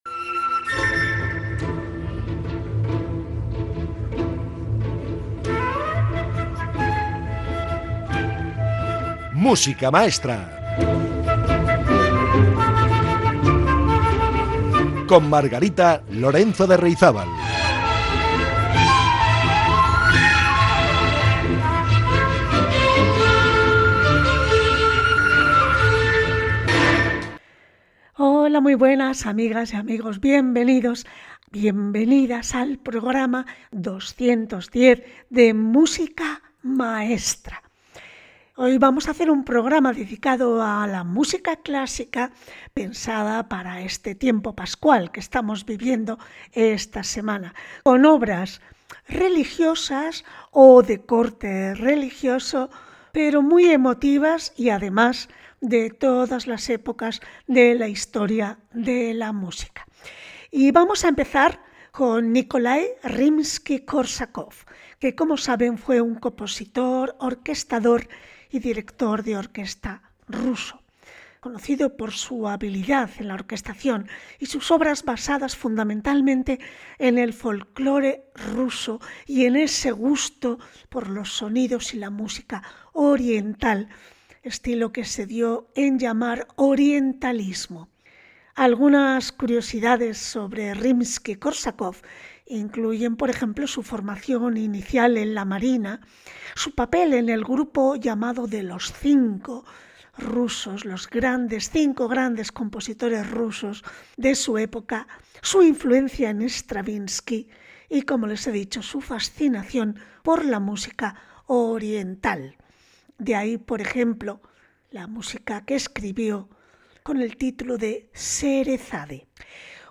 Música clásica para tiempo de Pascua